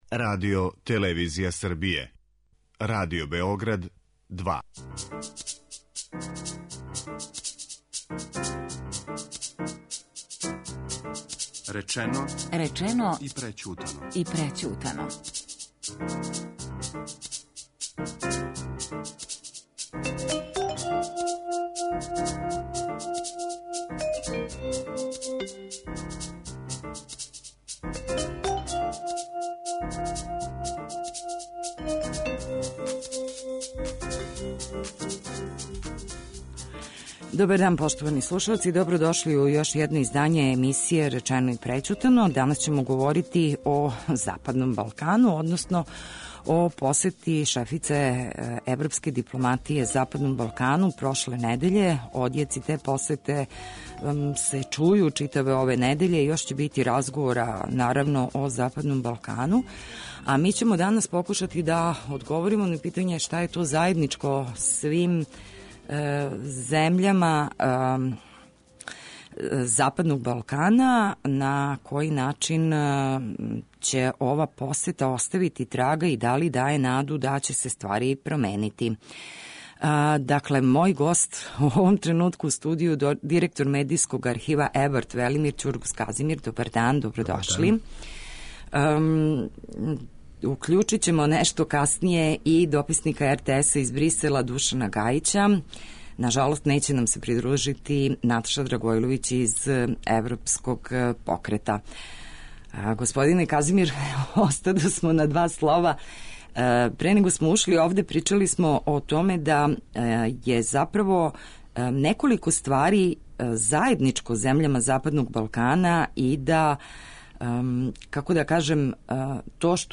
Гости у студију